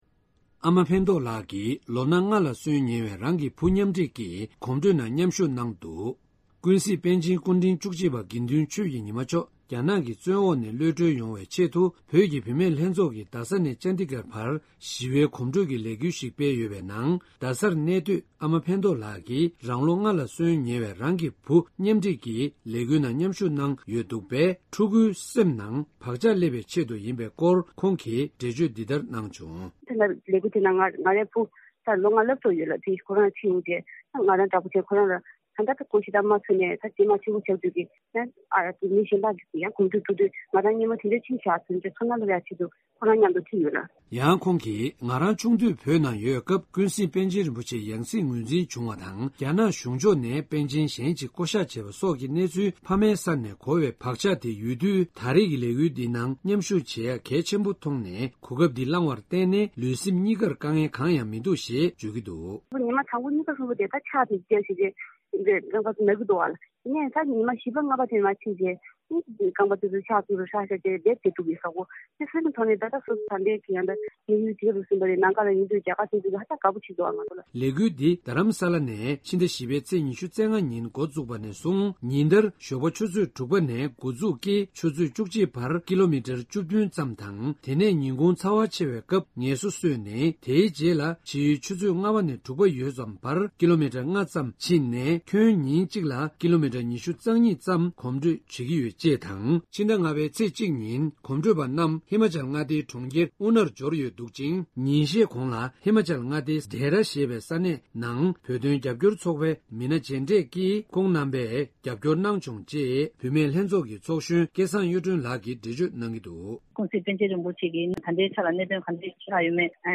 ས་གནས་ནས་བཏང་བའི་གནས་ཚུལ